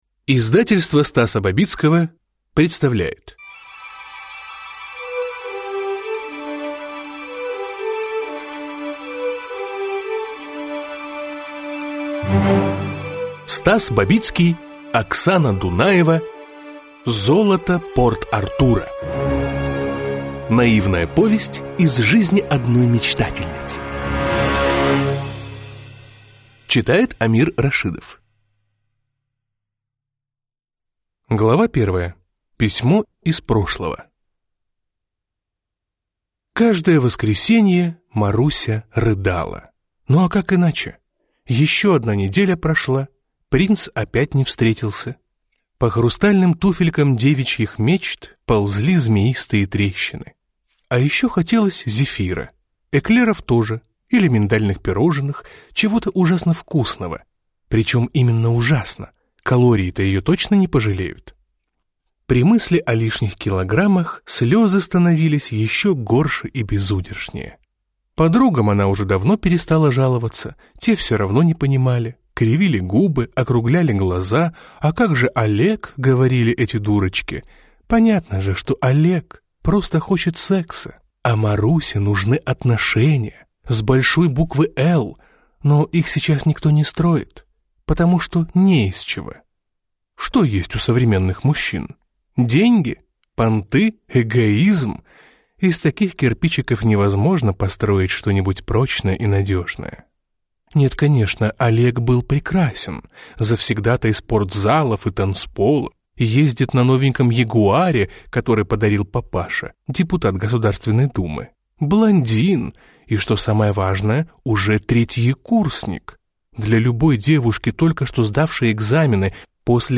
Аудиокнига Золото Порт-Артура | Библиотека аудиокниг